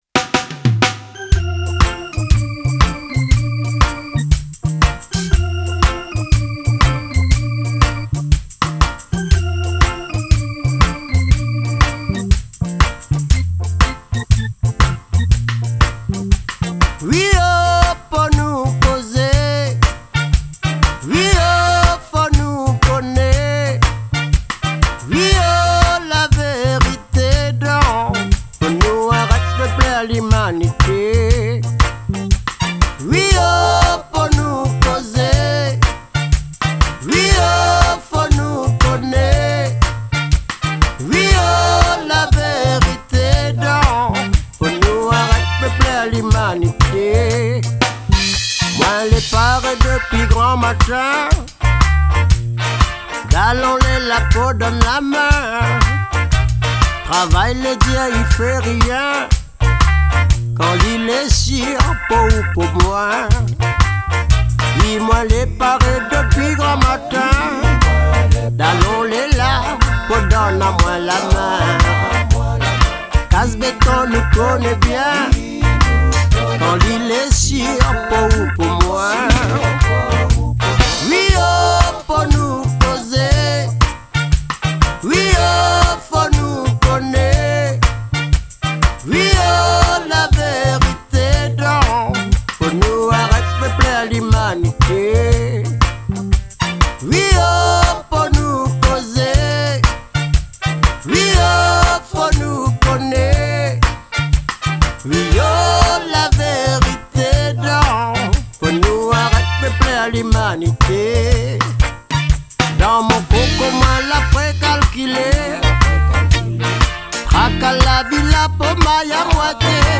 : Chanson engagée, Reggae, fusion